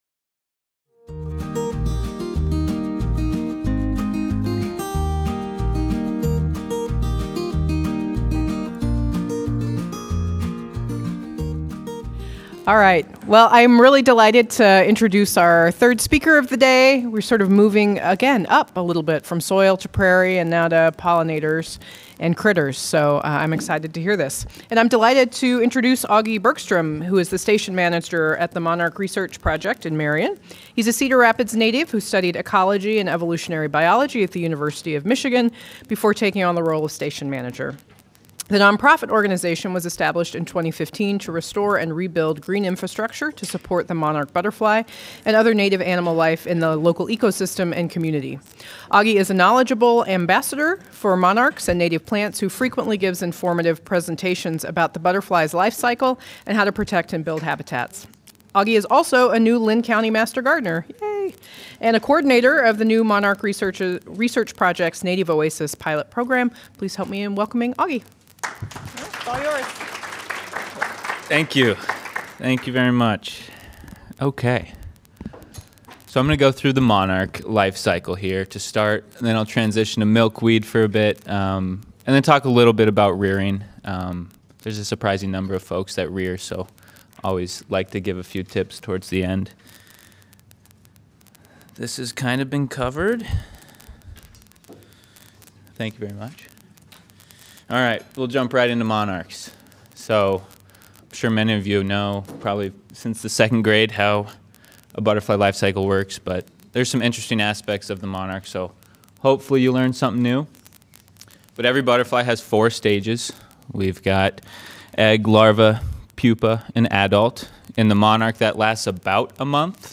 The Monarch Research Project is a non-profit organization seeking to add native pollinator habitat and reestablish the monarch population in Linn County, Iowa, establishing a model for the whole country. This presentation is part of the Johnson County Master Gardeners Community Speaker Series, a program for gardeners and anyone looking to expand their knowledge about healthy ecosystems.